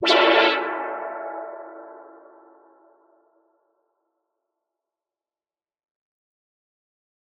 Chords_Amaj_02.wav